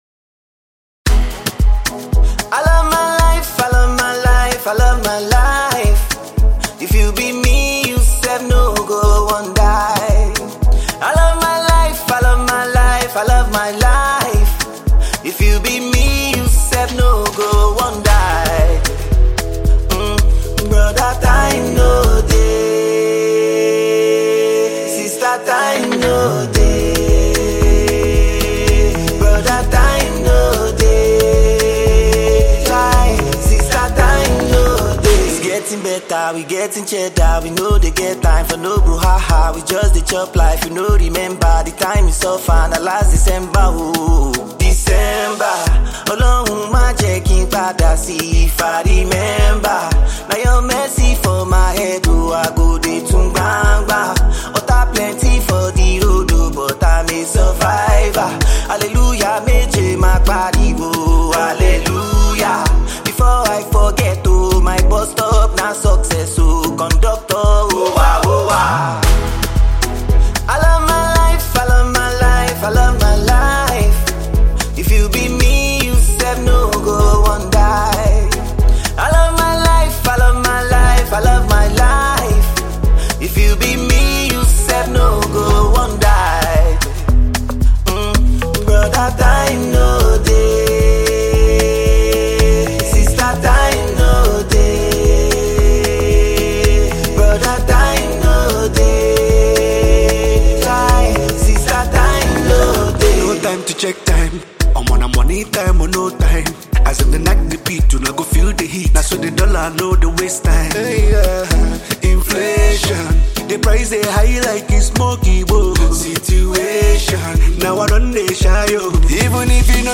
Region; Naija Music.